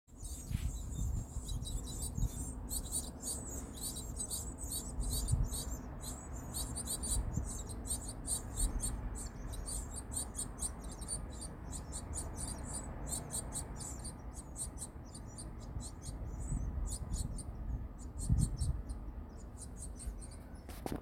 巣箱から子の元気な鳴き声が聞こえます